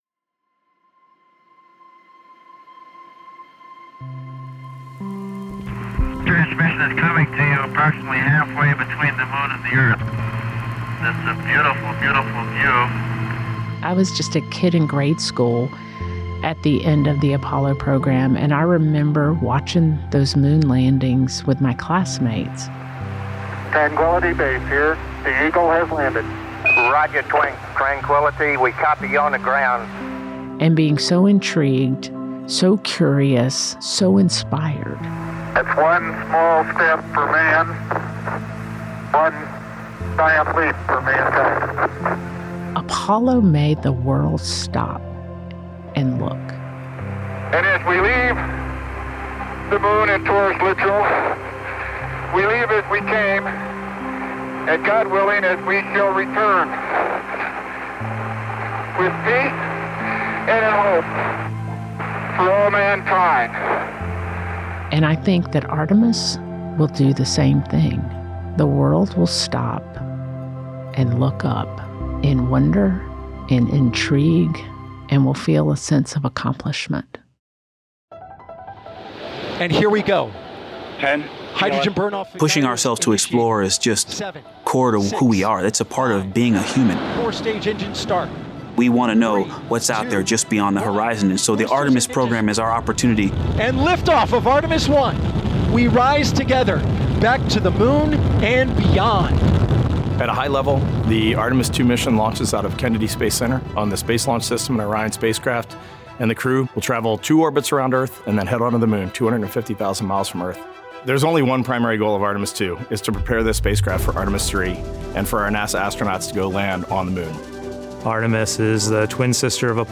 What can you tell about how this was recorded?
Releasing weekly, this five-part companion podcast shares exclusive astronaut interviews and immersive field recordings from unique locations across the agency, revealing the incredible teamwork, passion, and problem-solving fueling humanity’s return to the Moon, and beyond.